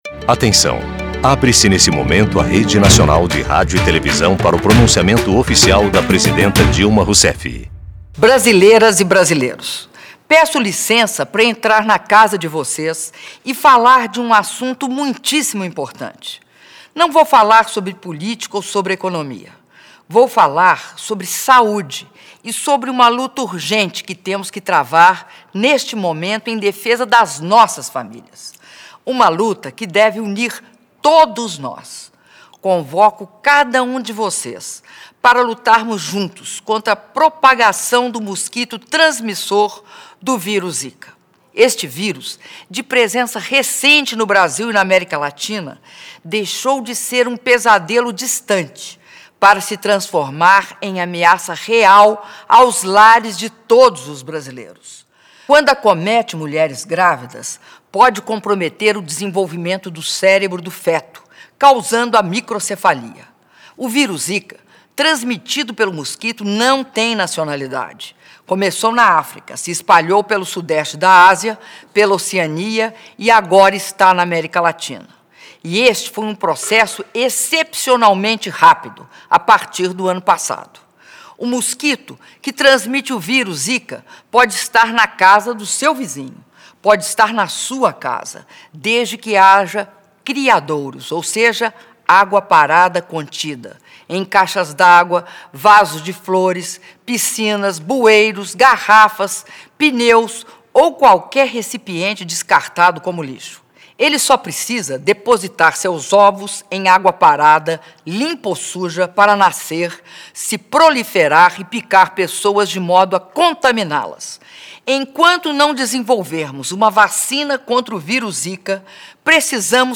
Áudio do pronunciamento da Presidenta da República, Dilma Rousseff, em cadeia nacional de rádio e televisão, sobre o vírus zika (06min12s) — Biblioteca